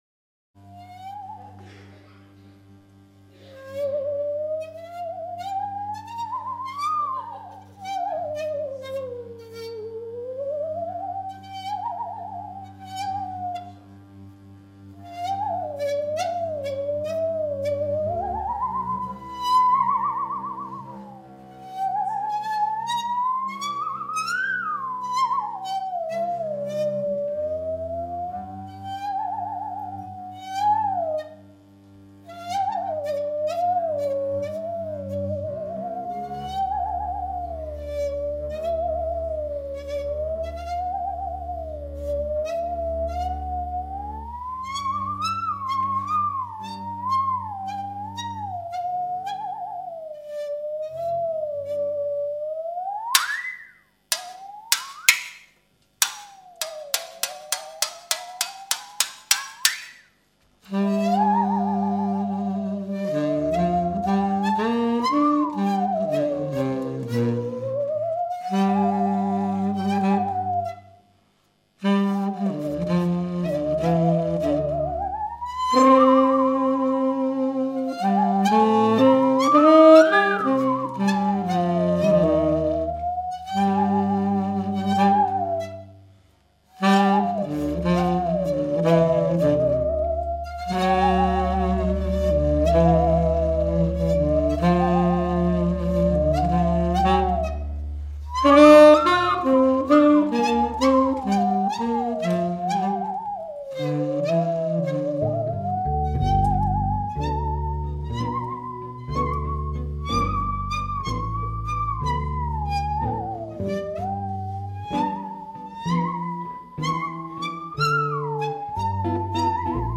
live
Halle 96, Veitsbronn am 22.2.2014